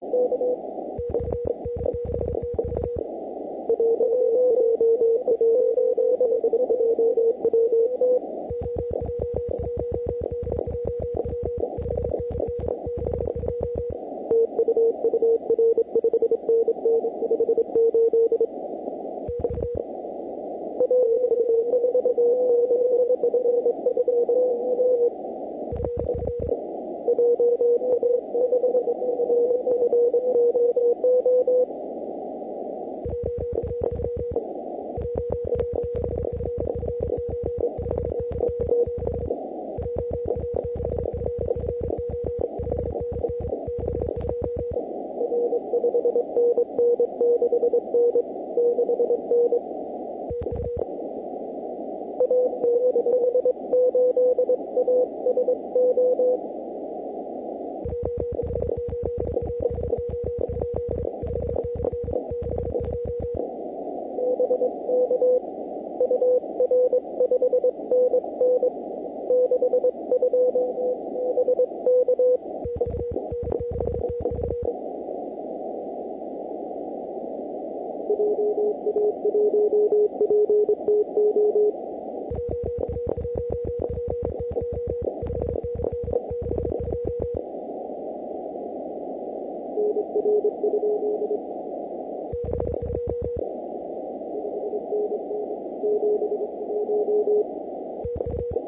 Please ignore the RFI artifacts during transmissions, and just listen to
"contester ears," even though the stations are quite close to each other.